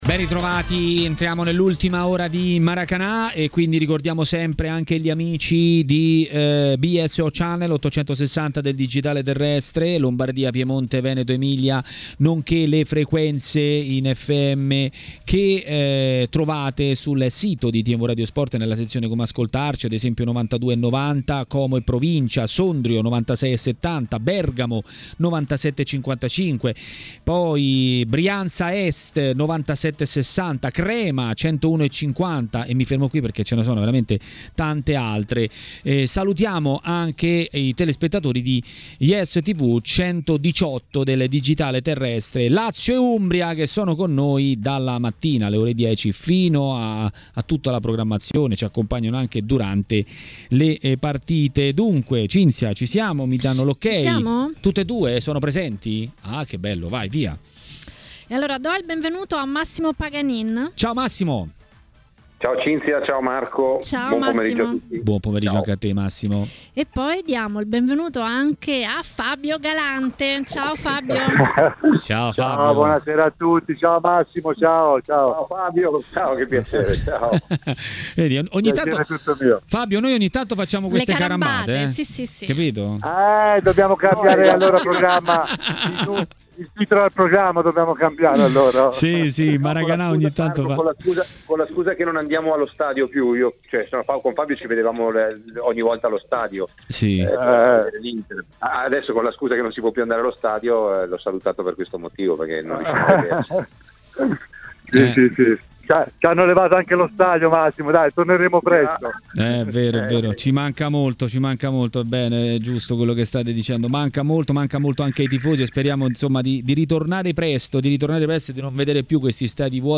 L'ex calciatore Fabio Galante ha commentato così l'Inter e l'impegno di stasera a TMW Radio, durante Maracanà.